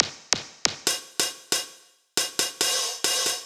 Lazer Zap Hats 138bpm.wav